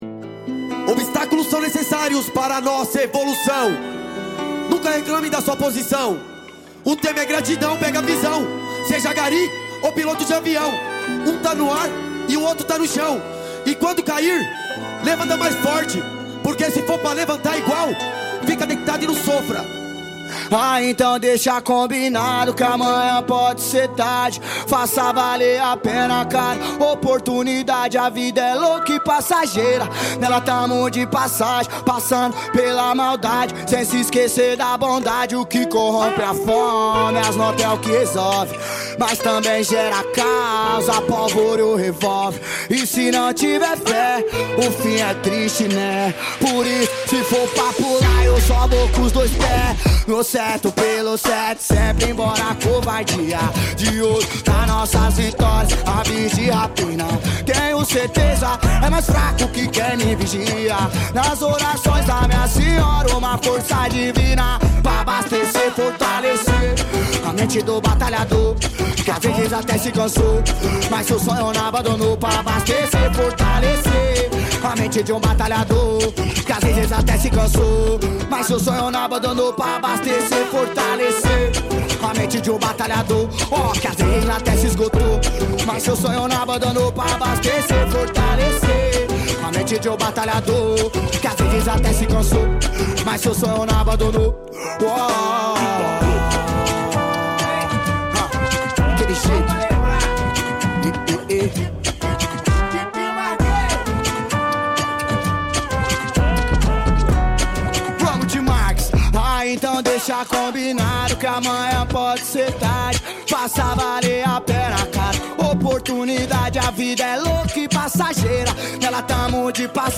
2025-03-22 15:39:48 Gênero: Trap Views